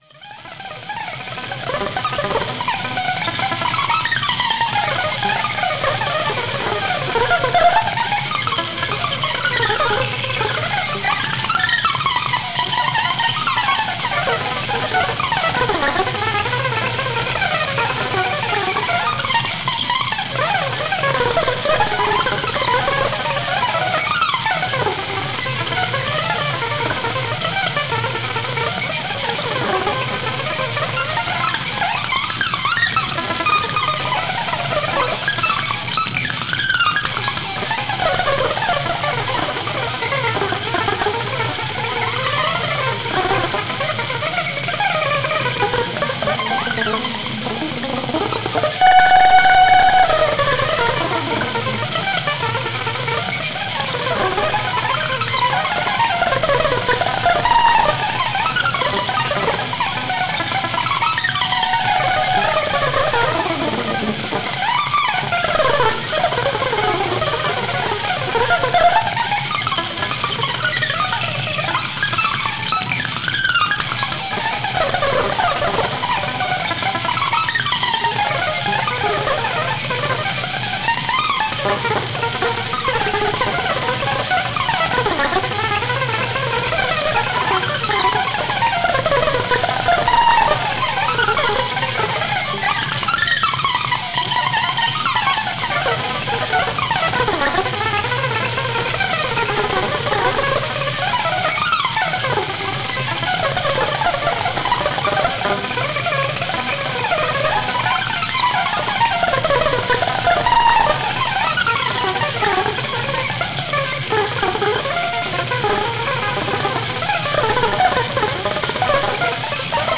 , orchestrated Glossolalia.
A large amount of automatism melodies
which was given out from the ultra high-speed guitar playing
is edited, arranged and unified by computer.